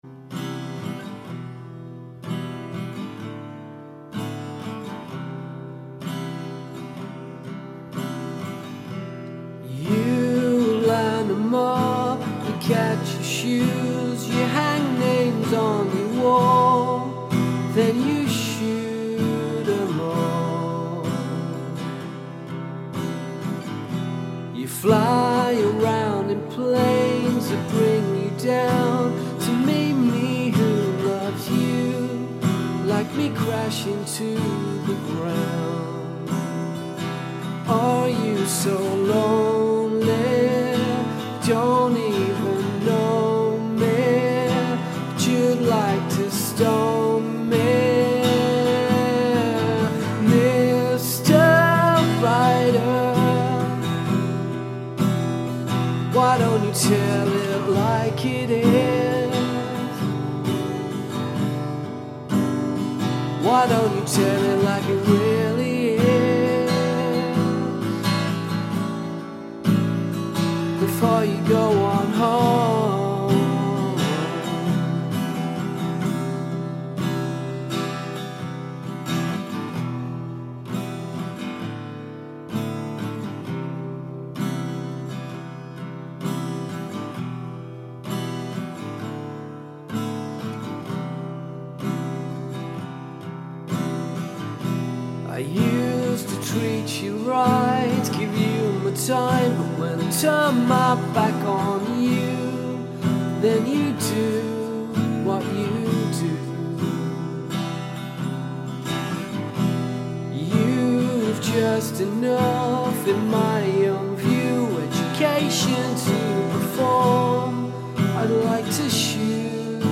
Acoustic guitar cover version.